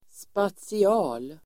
Ladda ner uttalet
spatial adjektiv, spatial Uttal: [spatsi'a:l] Böjningar: spatialt, spatiala Definition: som har att göra med utsträckning i rummet Exempel: spatial uppfattning (spatial perception) spatial adjektiv, rums- , spatial